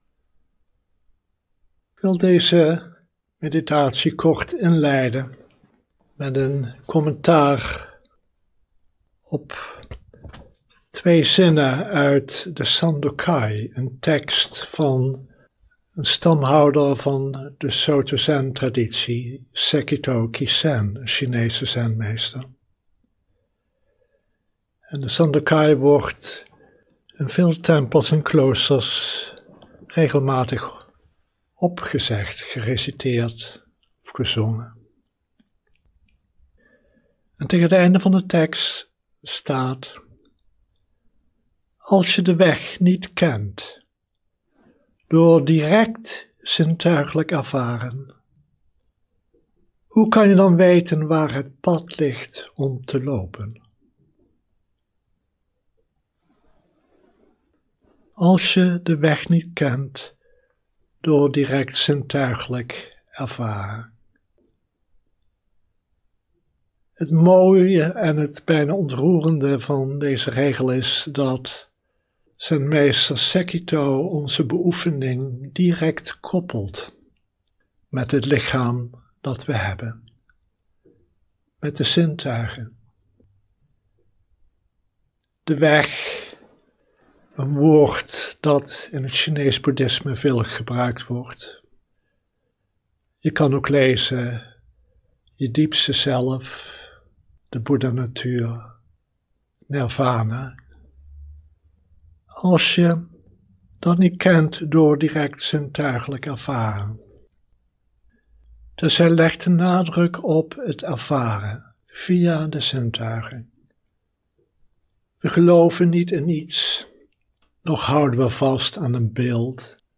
Livestream opname